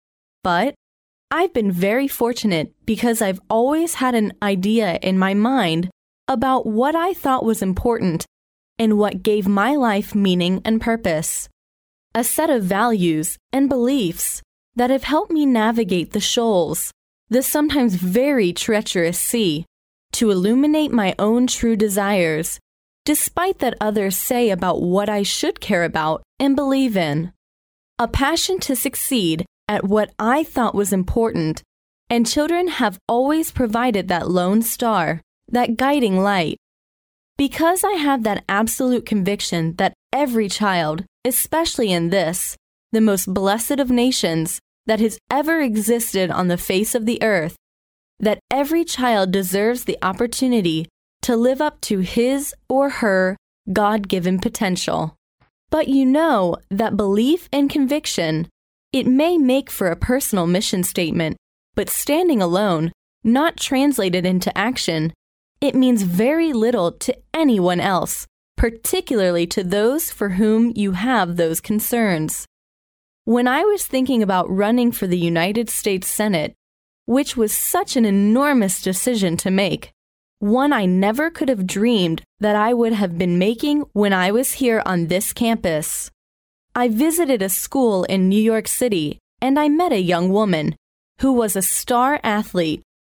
借音频听演讲，感受现场的气氛，聆听名人之声，感悟世界级人物送给大学毕业生的成功忠告。